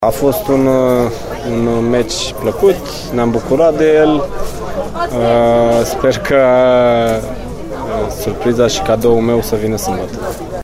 cu declaraţii după meci